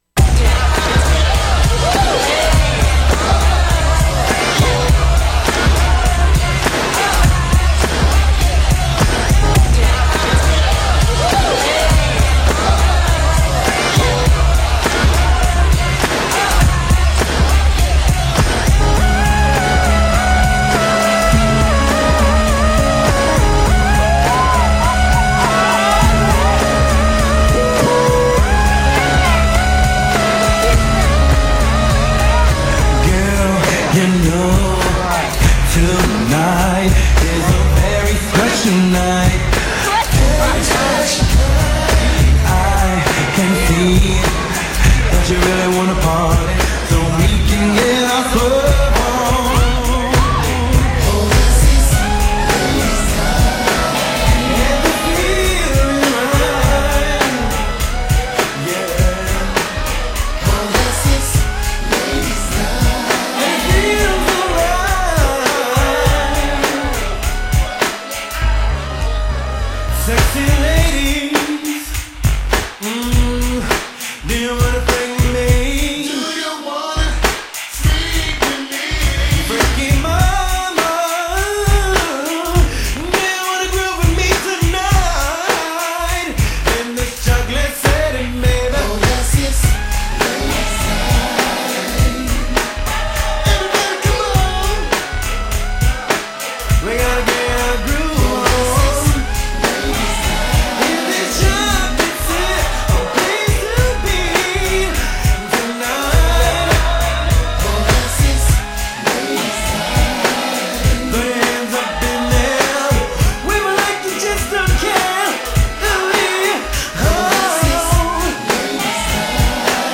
RnB
Clean version